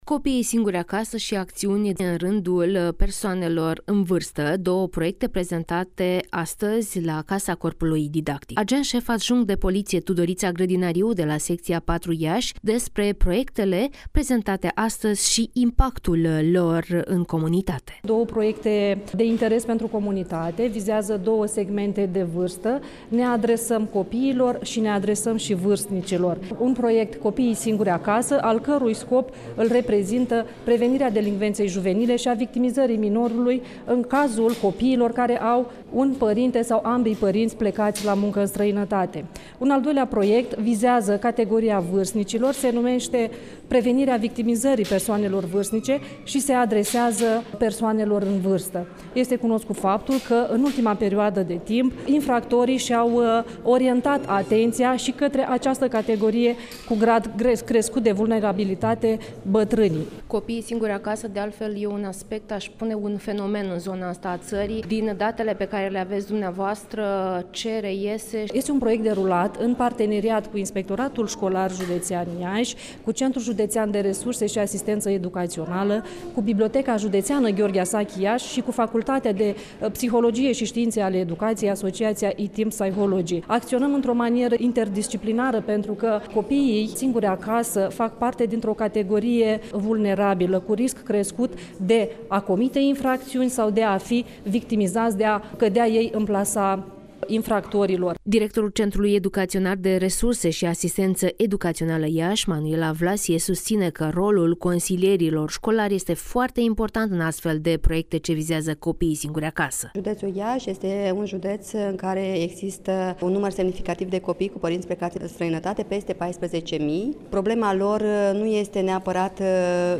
(REPORTAJ) Doar 77 de consileri școlari se ocupă de copiii singuri acasă din județul Iași